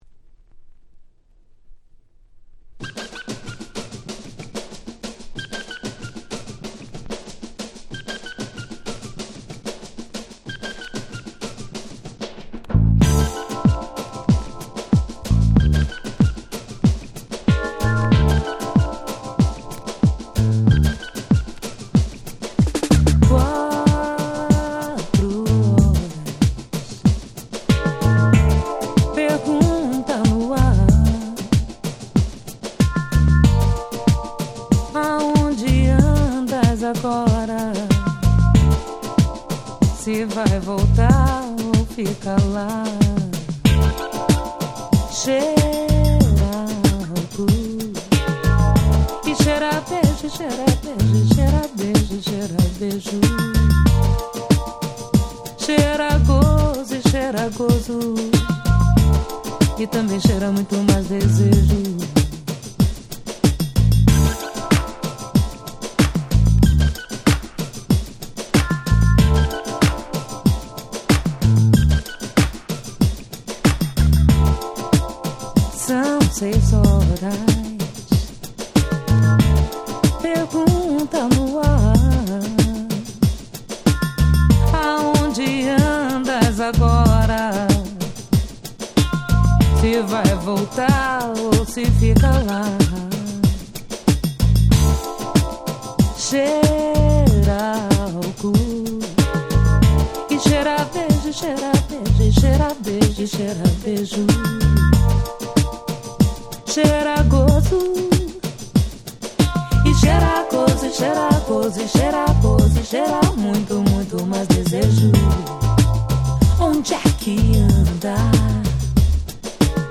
切ないボッサチューンなB-1(2000)
ボーダーブレイカーズ Brazilian Bossa